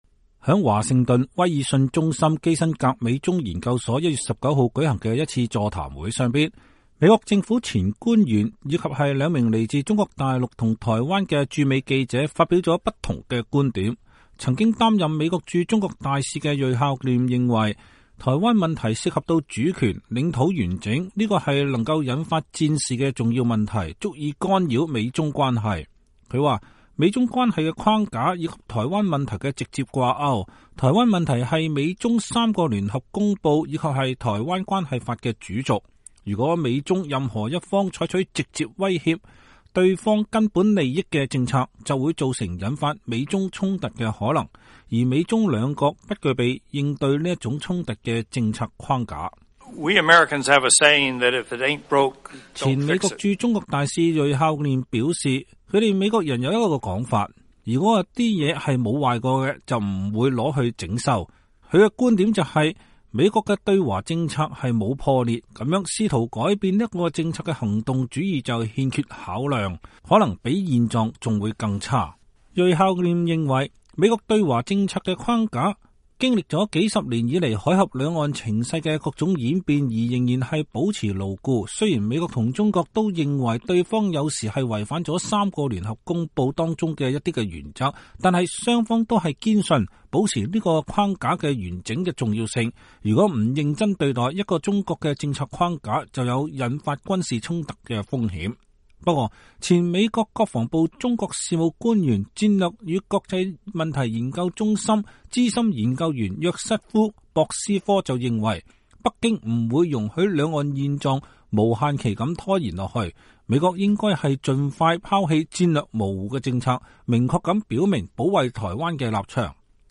在華盛頓威爾遜中心基辛格美中研究所1月19日舉行的一次座談會上，美國政府前官員和兩名來自中國大陸和台灣的駐美記者發表了不一樣的觀點。